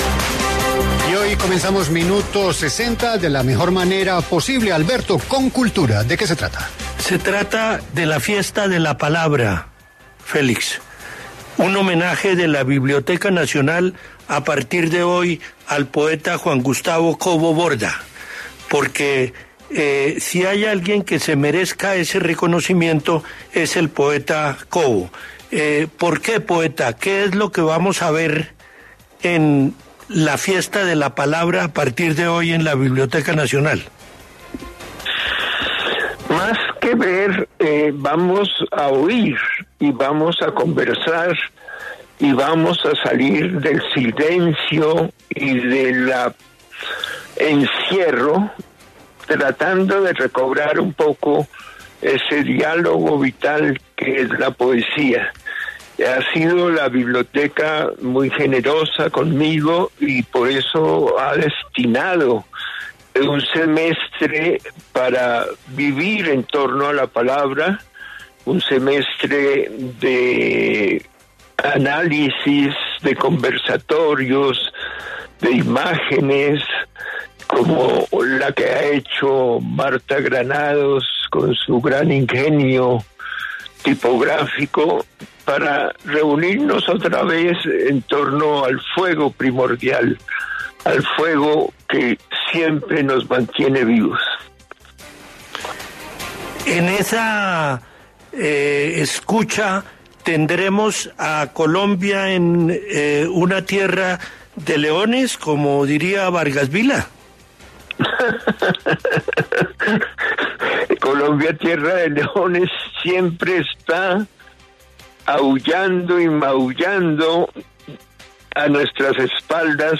En diálogo con La W, el poeta Juan Gustavo Cobo Borda conversó sobre el homenaje que le rendirá la Biblioteca Nacional.